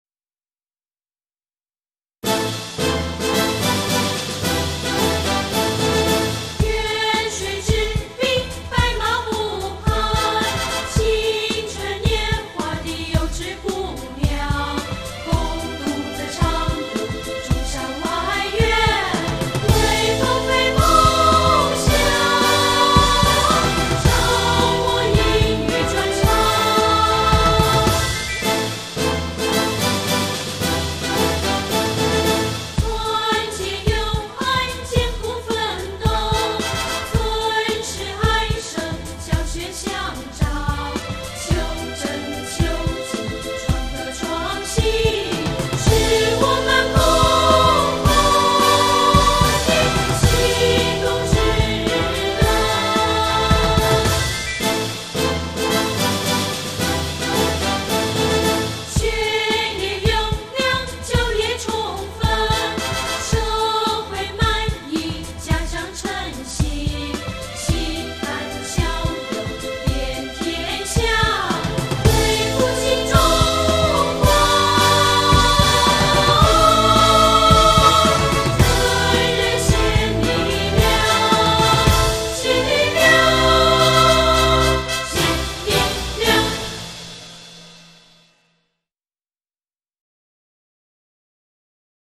常德中山外院院歌(合唱版)  另存为